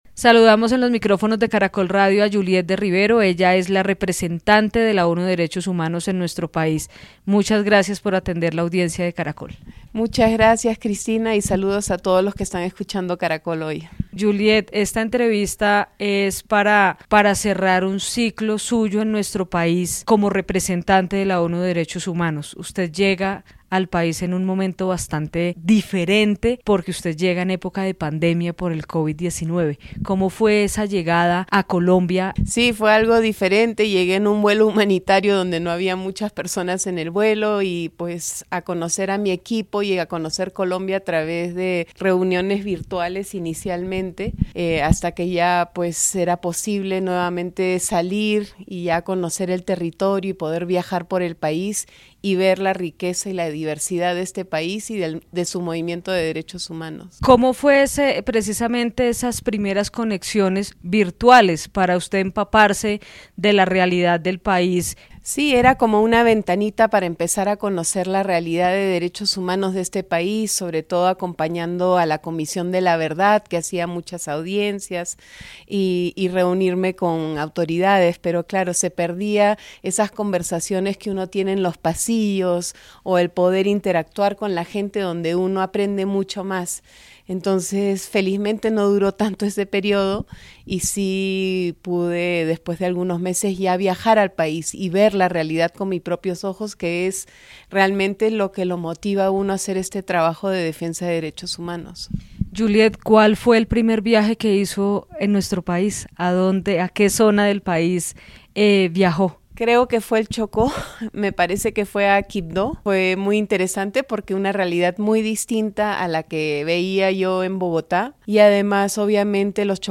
Juliette De Rivero cumple su misión en nuestro país después de más de cuatro años, en su última entrevista como representante de ONU Derechos Humanos les dejó un mensaje a las víctimas, a los grupos armados y al Estado colombiano